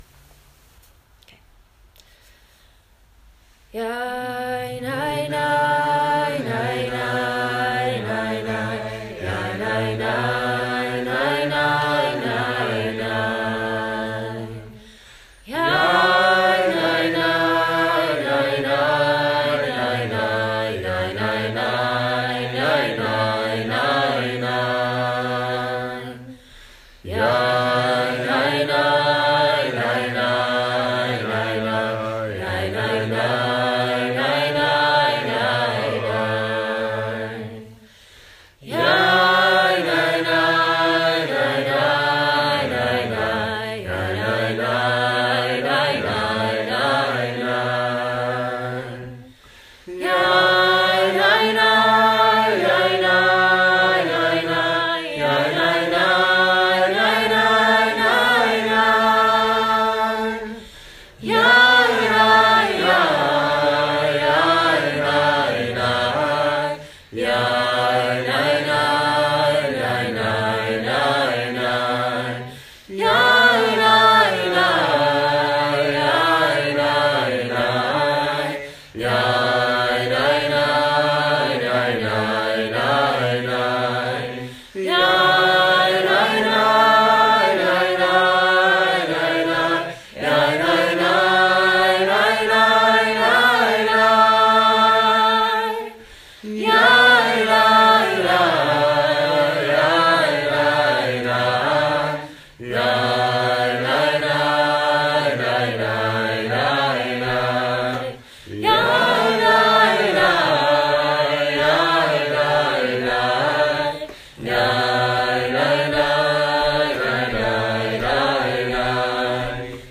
He loved these wordless tunes that spirited him.